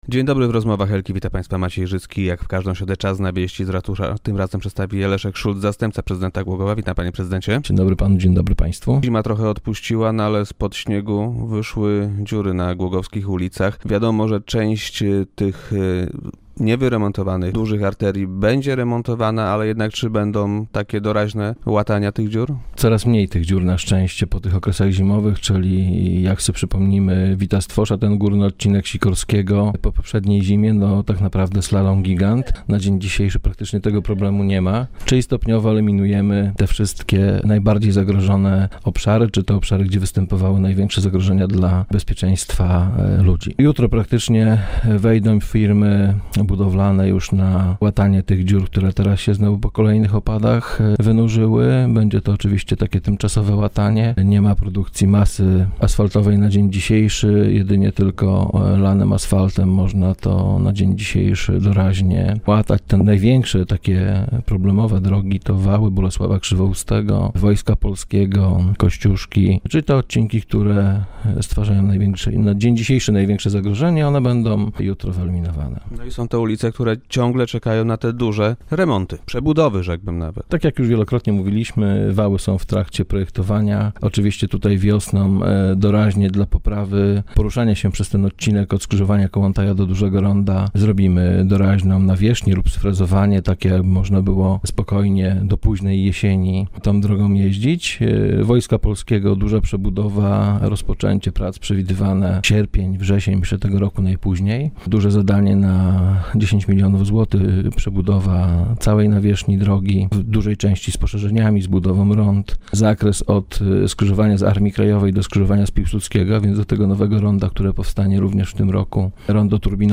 - Postanowiliśmy wspomóc lokalne firmy, przeznaczając więcej pieniędzy na inwestycje remontowe - powiedział Leszek Szulc, zastępca prezydenta Głogowa, który był dziś gościem Rozmów Elki.
- Środki przeznaczone na remonty dróg i chodników w mieście zwiększyliśmy z sześciu do niemal dziesięciu milionów złotych. Dużo pieniędzy przekazanych zostanie także do Zakładu Gospodarki Mieszkaniowej w ramach dotacji, na remonty ciągów pieszo - jezdnych przy budynkach mieszkalnych - powiedział w radiowym studio Leszek Szulc.